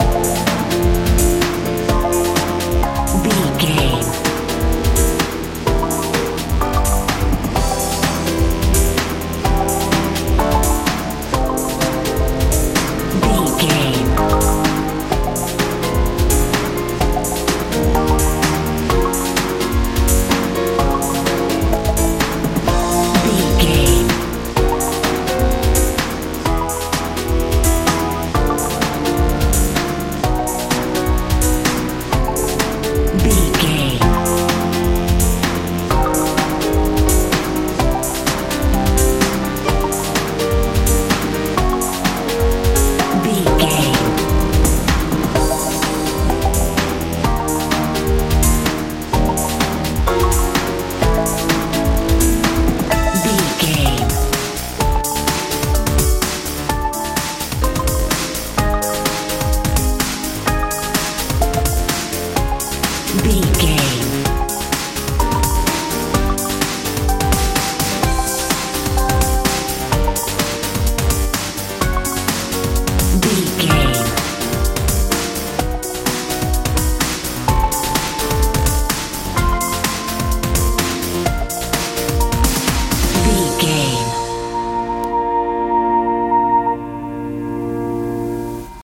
modern dance
Aeolian/Minor
bouncy
dreamy
bass guitar
drums
saxophone
80s
sweet